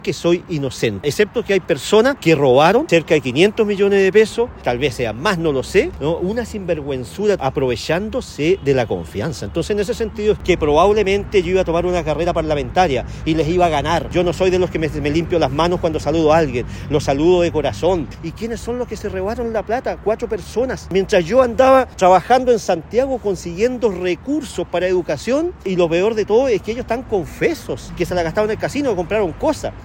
Salió del lugar, esquivo en primera instancia a la prensa, con quien finalmente conversó por más de 15 minutos, ininterrumpidamente.
En conversación con La Radio, reiteró su inocencia, tratando de sinvergüenzas a quienes fueron en su momento personas de su extrema confianza.